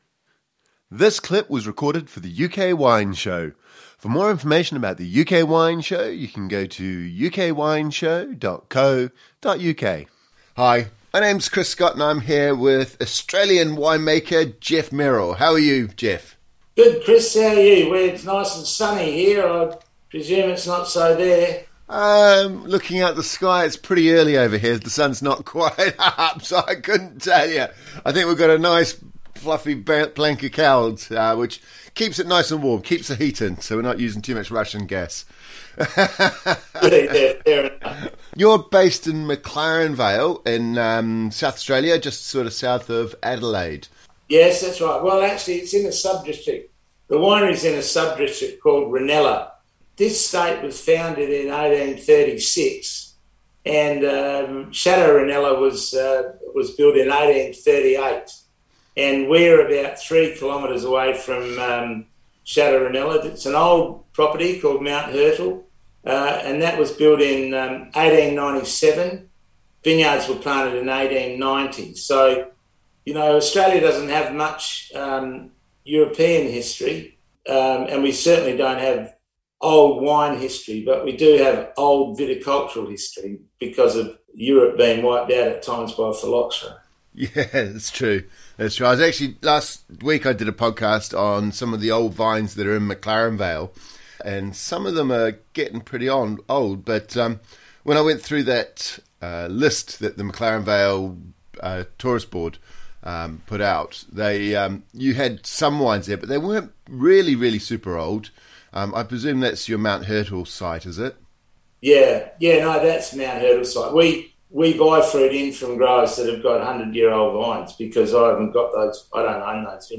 » Listen to the full UK Wine Show